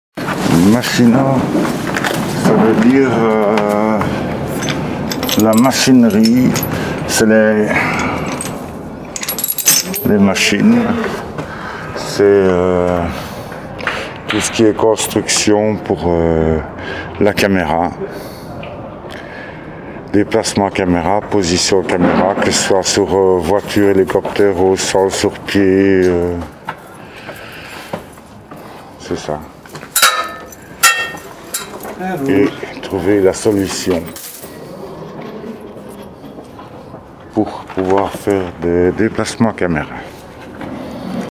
Enregistré lors du tournage du film La Cage RPZ
uitspraak